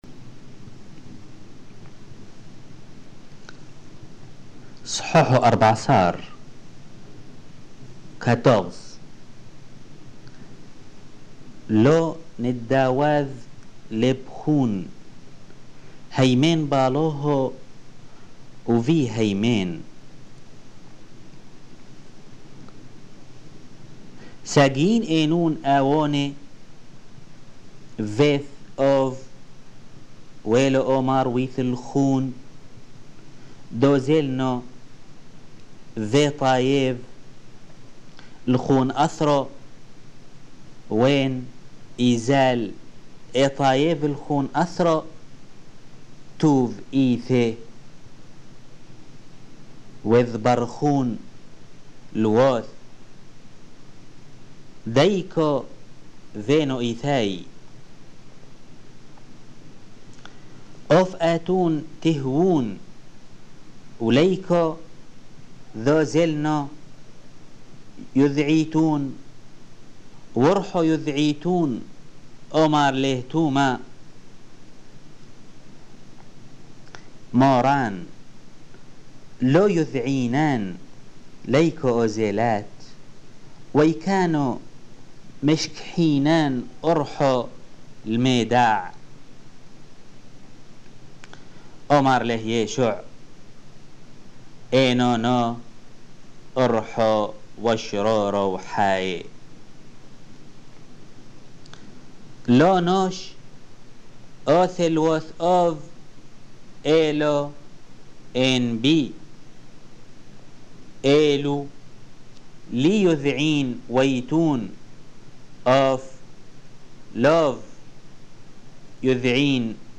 Lecture de l'évangile de Jean, chap. 14-18 en langue syriaque (peshitta)